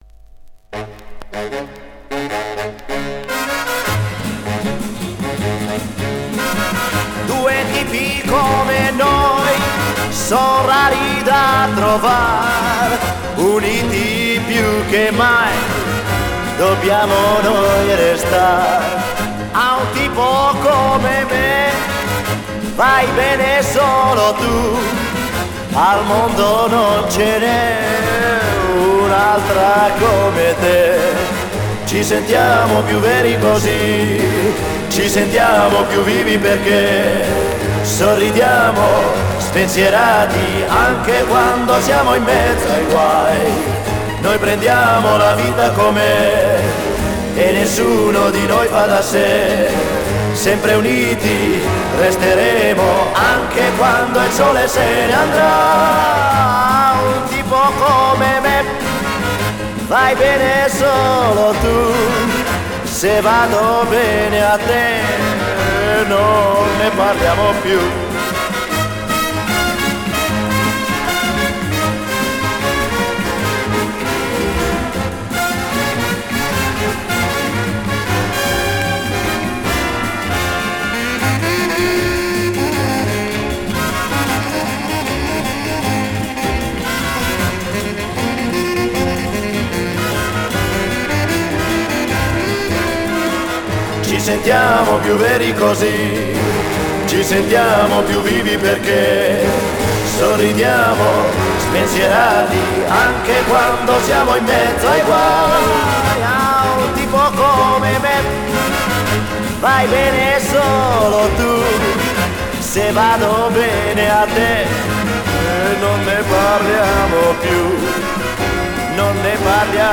Жанр: Rock, Funk / Soul, Pop
Стиль: Rock & Roll, Chanson, Pop Rock, Ballad